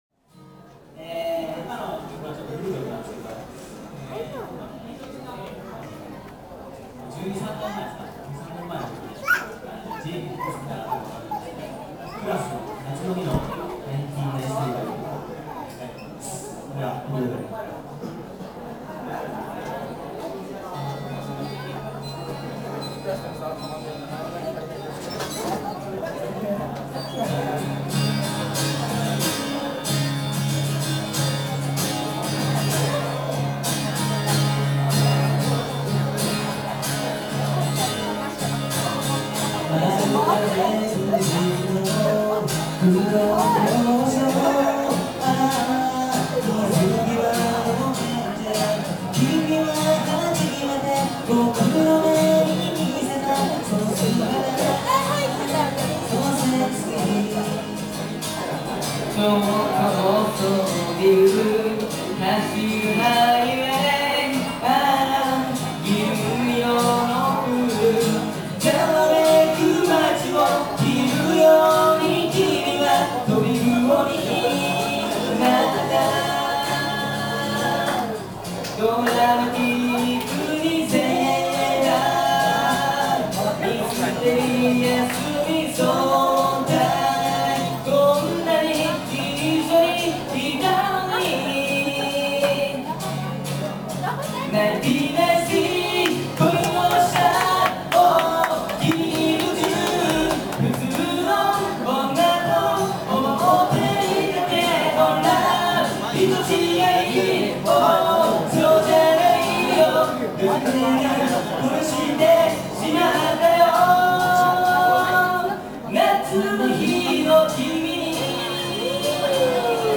【本番（ＹＭＣＡ祭ライブ音源）】
音質は低いですが、少しでもライブの雰囲気が伝われば嬉しいです(^^)。
持ち時間が限られていたのでテンポ良くいく必要があった。
ハモリのとこだけキーが高くなるので気張ってます(ーへー)。
使用したギター：Gibson J-45（半音下げチューニング）